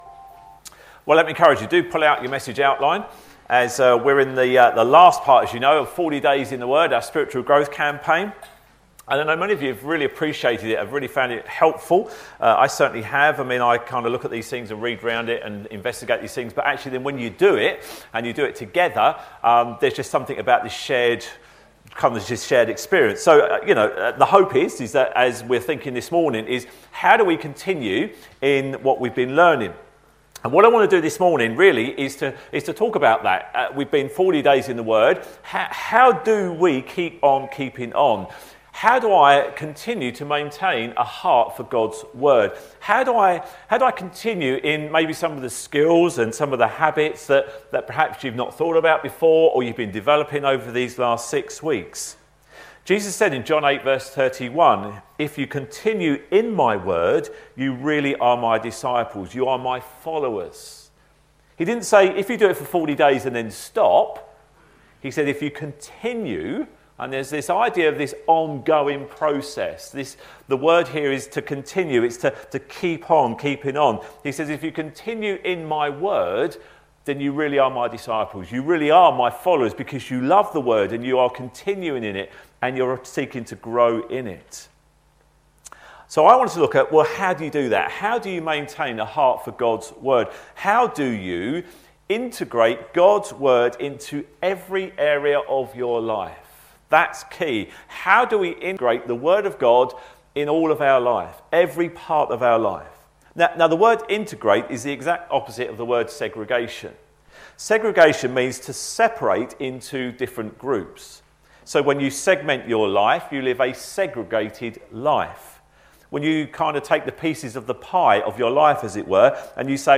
40 Days In The Word Sermons